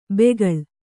♪ begaḷ